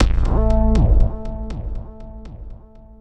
Slider Bass.wav